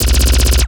LASRGun_Alien Handgun Burst_04_SFRMS_SCIWPNS.wav